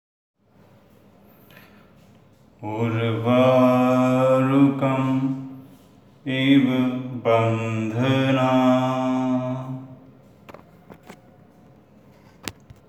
Chanting (slow for comprehension) –